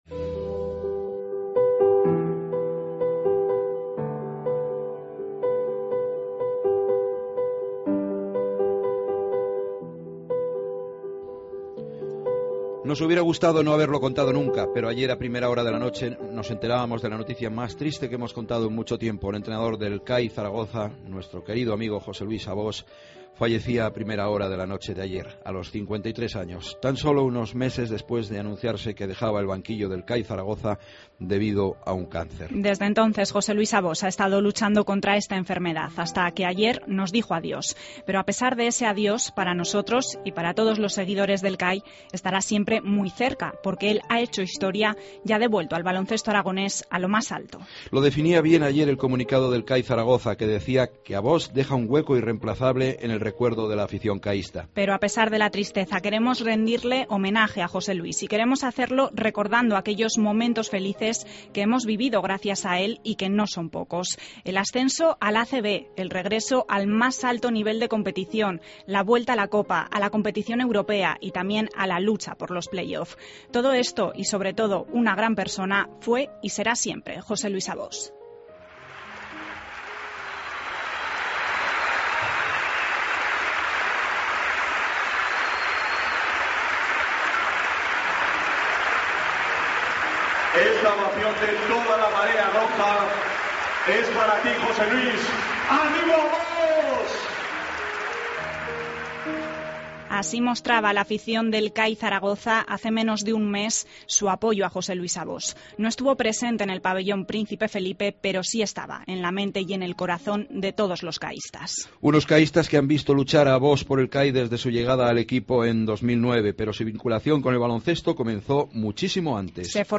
Deportes COPE en Zaragoza - Reportaje homenaje José Luis Abós 21-10-14.